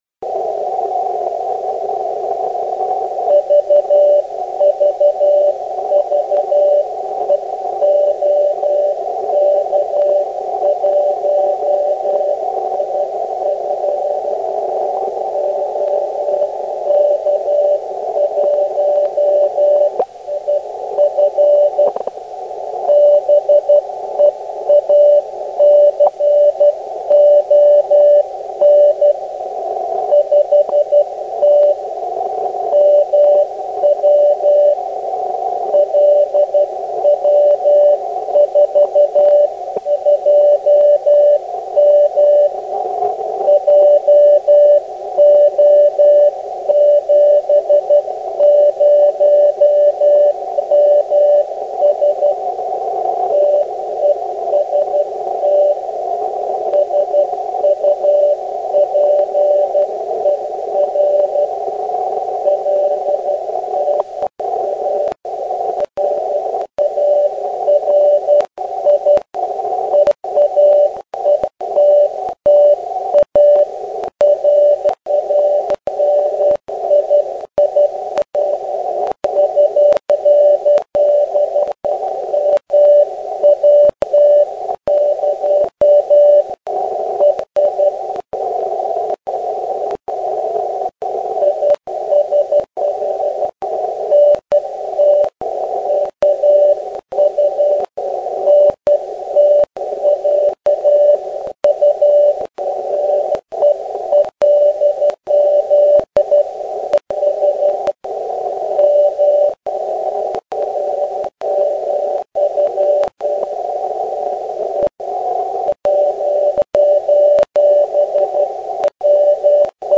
Že na 7028 KHz je aktivní maják 50mW asi víte.